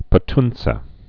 (pə-tntsĕ)